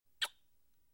Звуки воздушного поцелуя
Звук поцелуя, летящего по воздуху